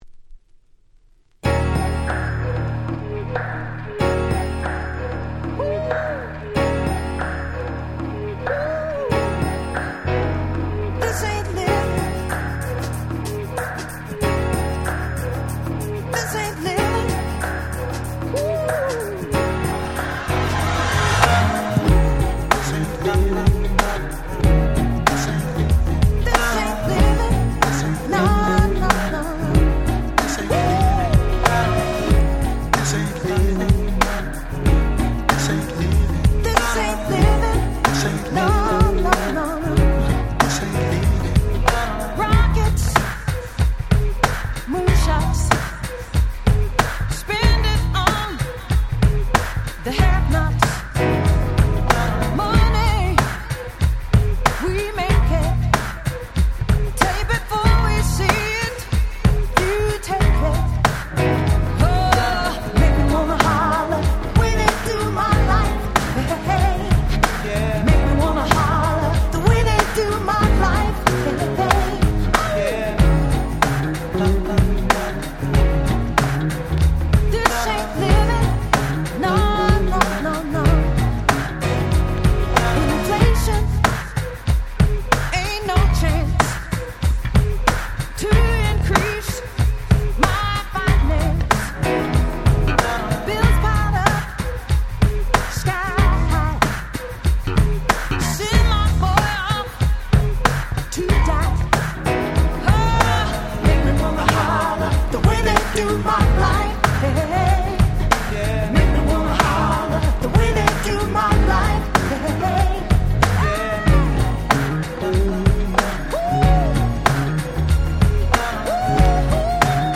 94' Very Nice R&B / Slow Jam !!